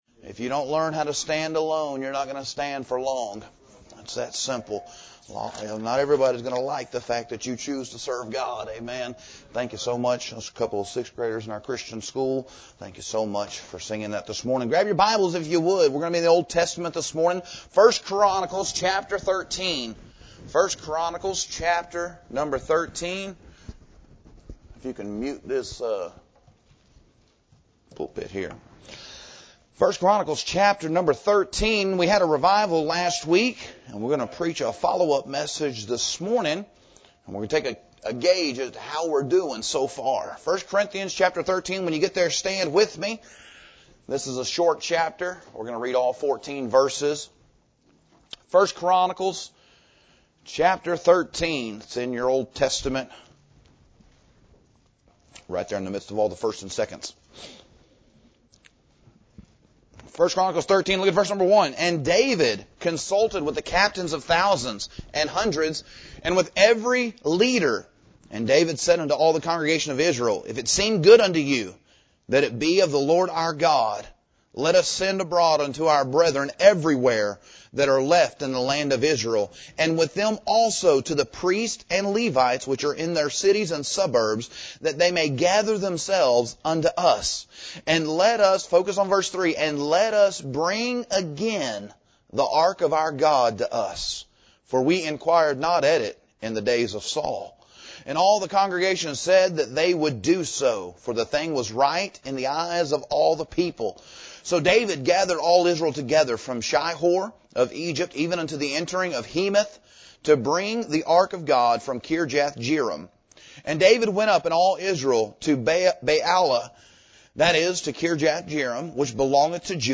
Read aloud: First Chronicles 13:1-14